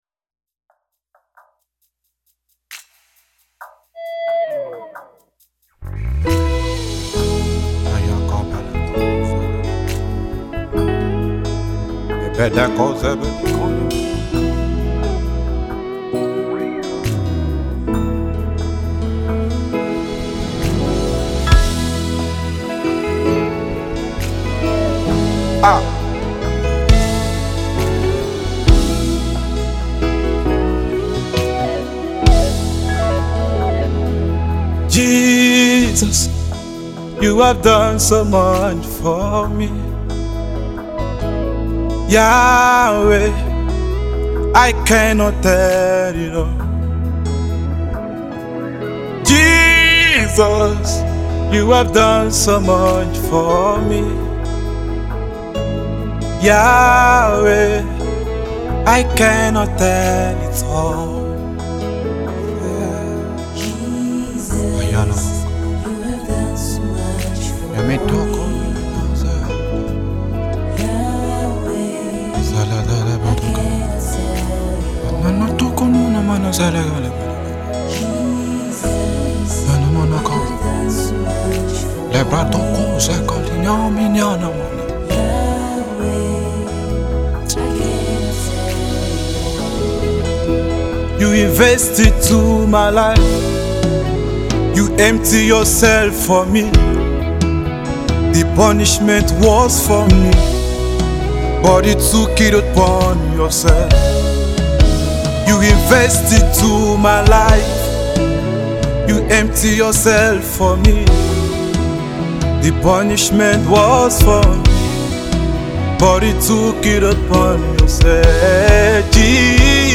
a song of testimony